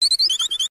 417Cry.wav